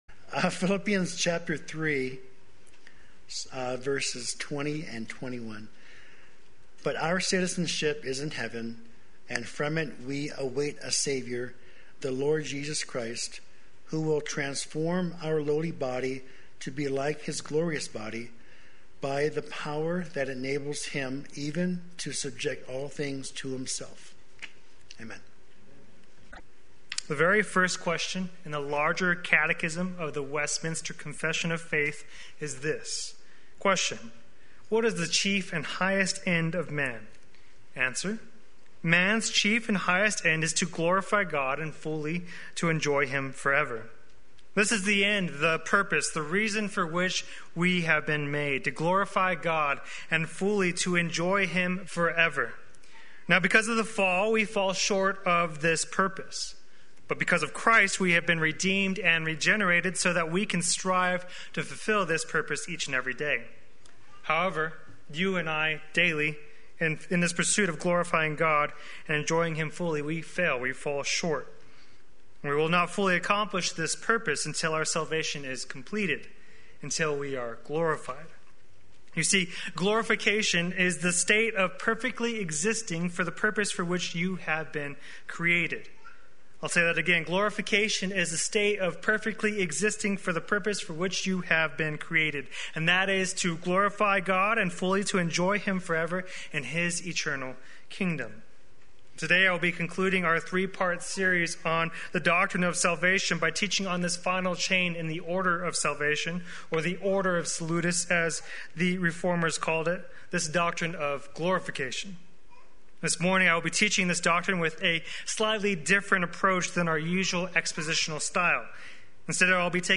Play Sermon Get HCF Teaching Automatically.
Paradise Restored Various verses Sunday Worship